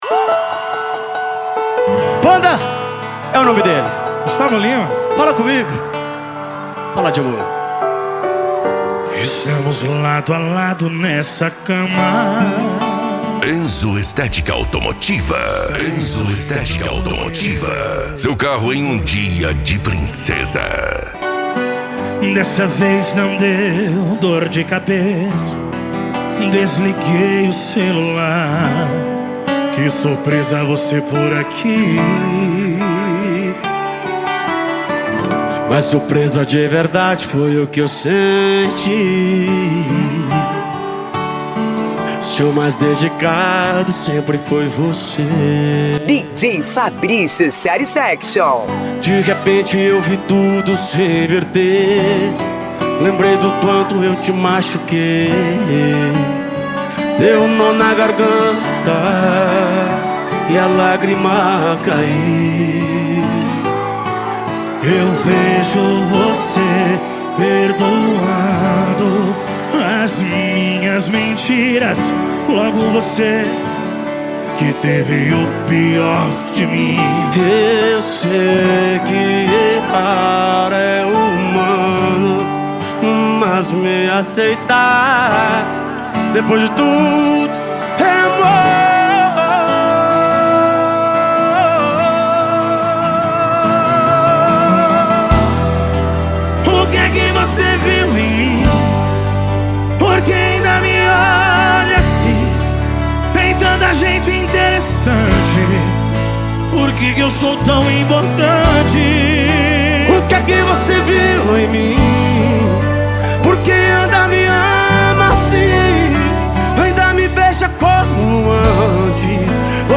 SERTANEJO
Sertanejo Raiz